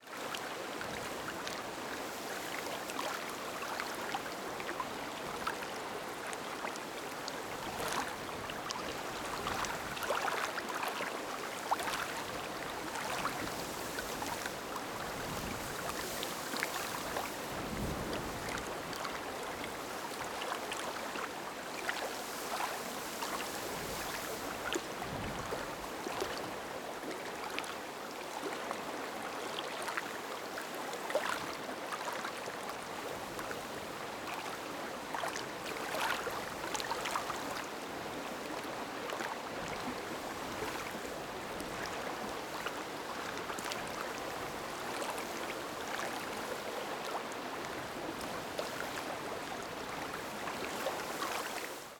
riverL.wav